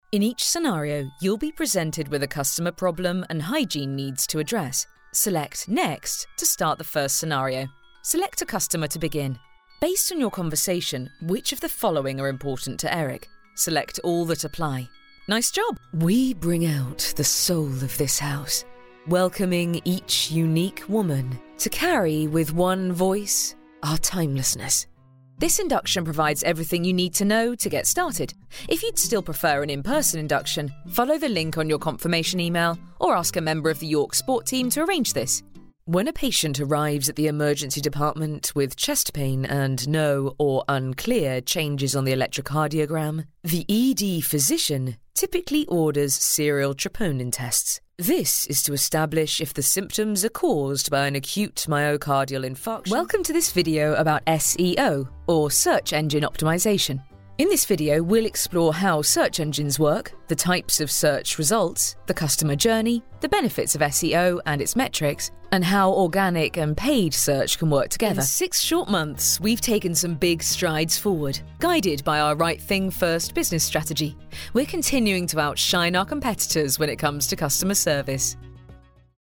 Female
English (British)
My natural vocal tone is sincere, friendly and direct with a clarity and warmth.
Corporate